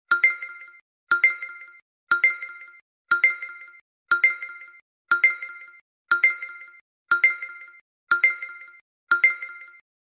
Countdown 10 to 0 | sound effects free download
Old and realistic automotive digital clock